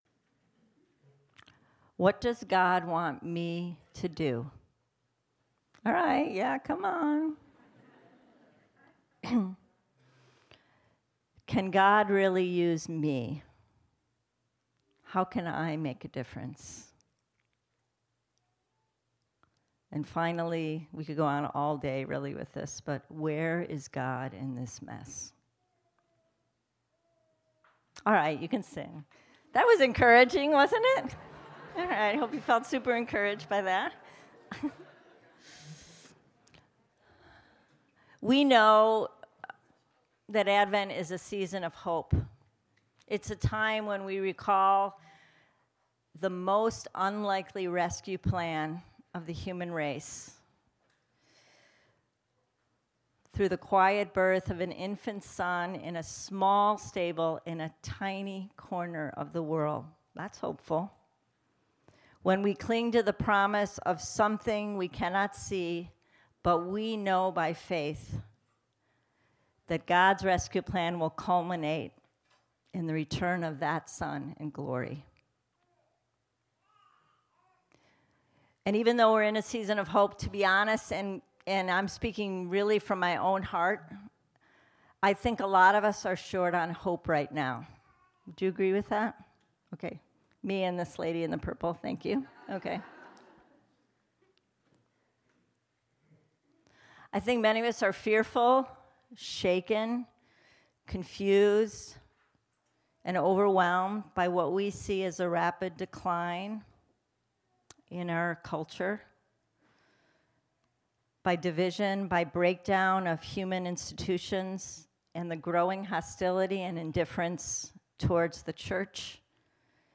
Women's Advent Retreat 2022